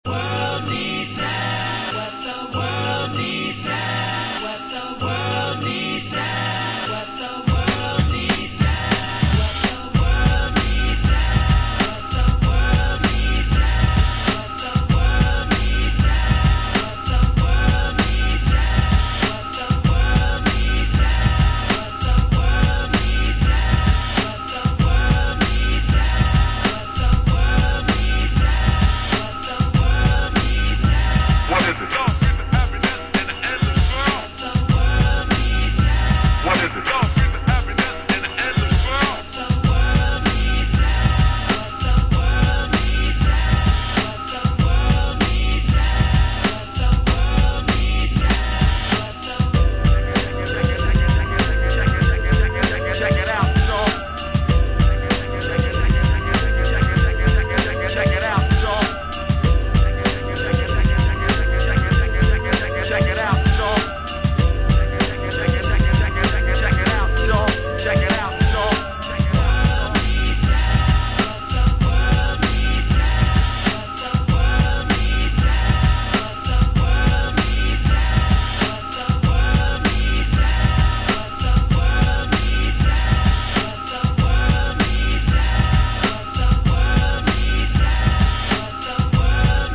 Classic hip hop instrumentals!
Hip Hop